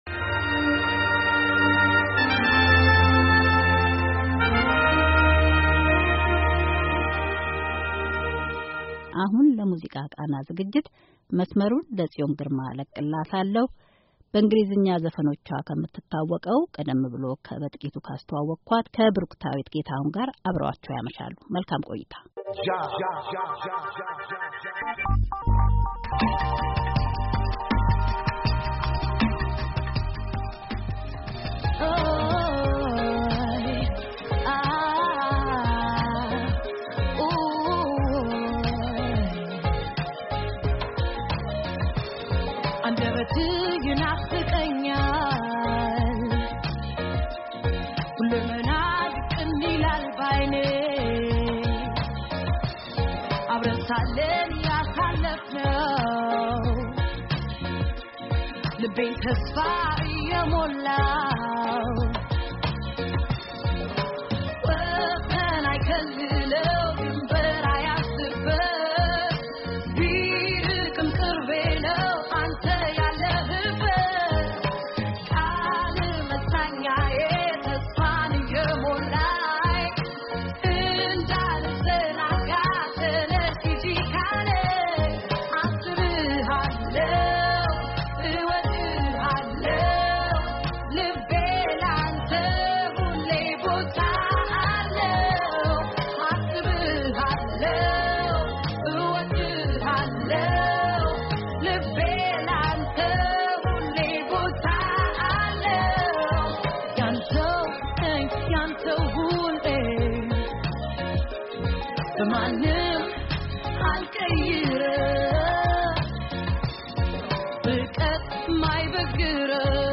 ብሩክታይት ጌታሁን (ቤቲ ጂ) ስለሥራዎቿ እያጫወትችን የሳምንቱን ሙዚቃ መርጣልች።